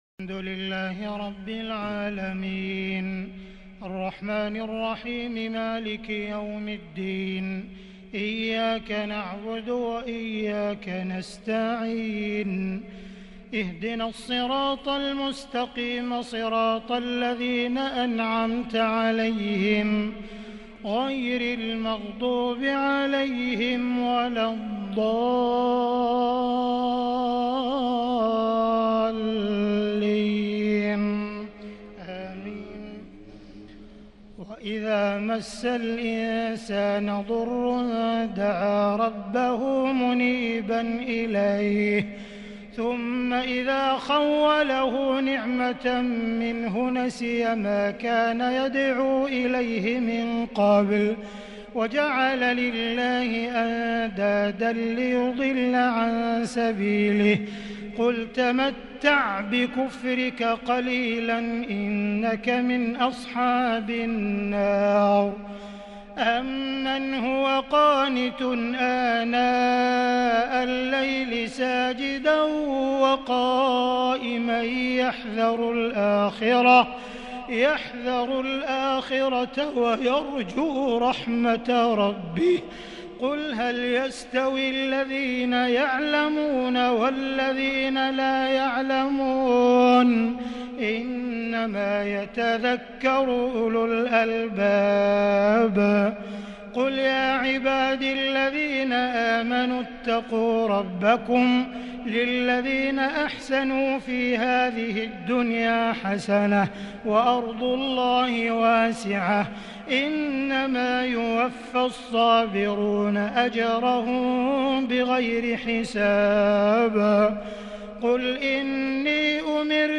تهجد ليلة 25 رمضان 1441هـ من سورة الزمر (8-52) | Tahajjud 25st night Ramadan 1441H Surah Az-Zumar > تراويح الحرم المكي عام 1441 🕋 > التراويح - تلاوات الحرمين